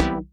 Misc Synth stab 07.wav